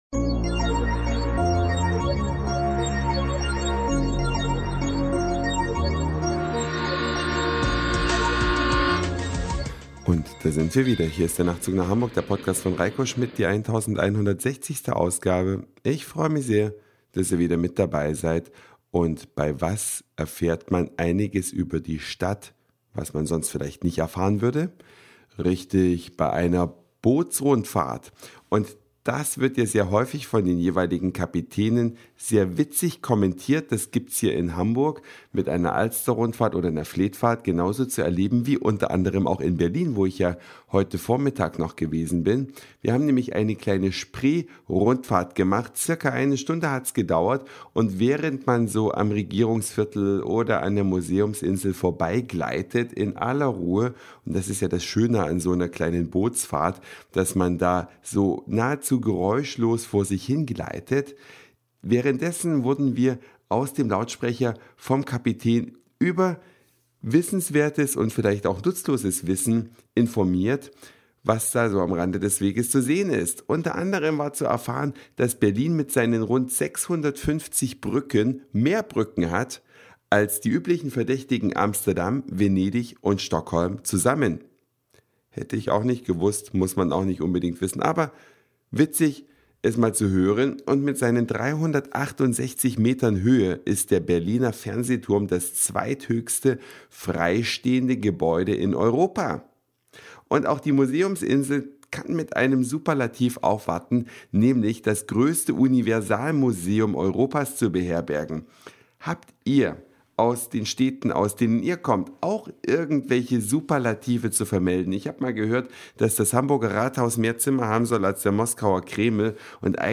Viel nutzloses Wissen an Bord des Dampfers auf der Spree.